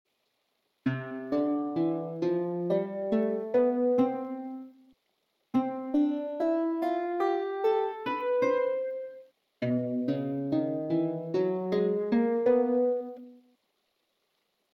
Misty_Mountains_Harp_Sound.mp3